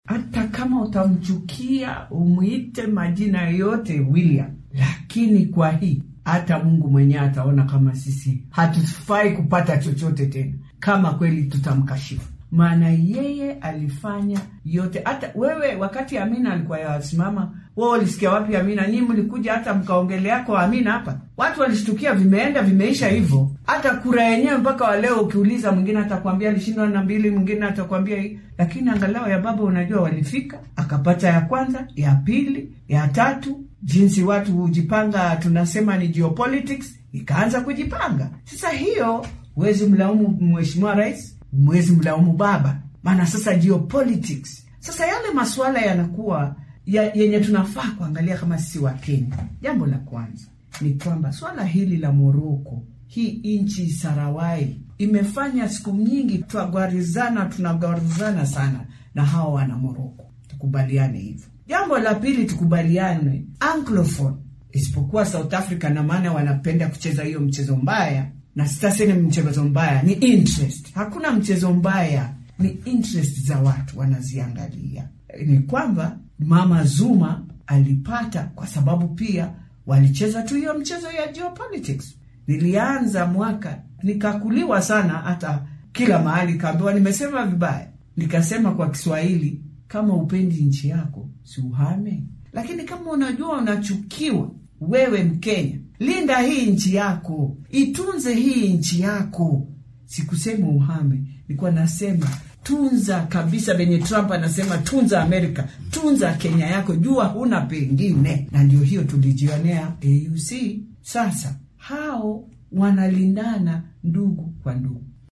Xildhibaanadda laga soo doortay deegaan baarlamaneedka Waqooyiga Dagoretti ee ismaamulka Nairobi Beatrice_Elachi oo wareysi gaar ah siinaysay idaacadda maxalliga ee Radio Citizen ayaa sheegtay in guul darradii Kenya ka soo gaartay u tartamidda xilka guddoomiyaha guddiga Midowga Afrika ee AUC-da aan lagu eedeyn karin Raila Odinga iyo madaxweynaha dalka William Ruto maadaama ay labaduba dadaaleen.